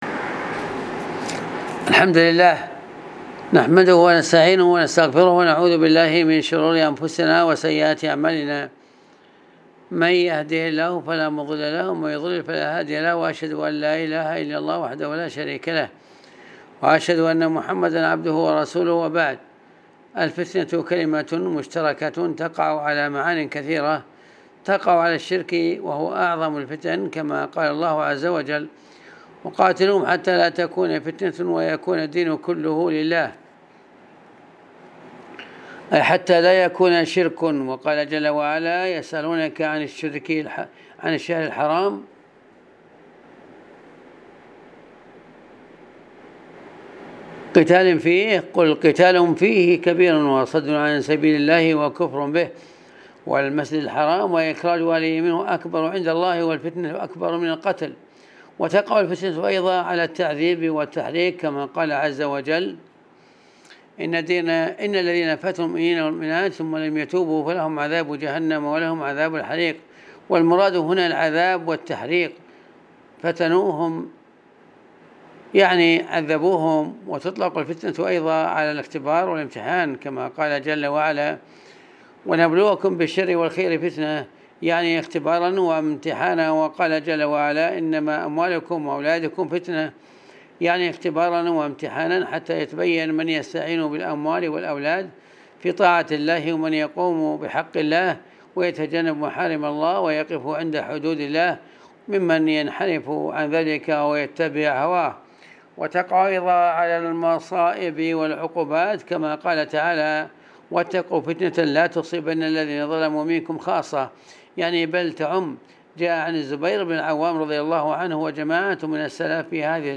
المحاضرة